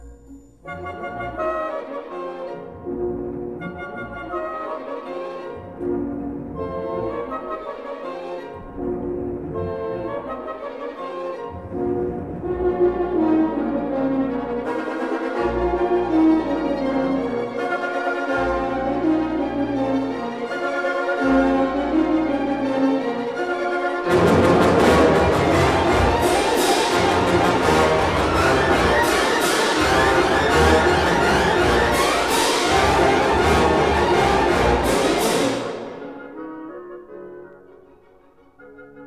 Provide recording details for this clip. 1961 stereo recording